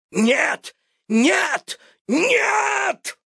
Образцы озвучания, прошедшие визирование у Супера и допущенные к опубликованию: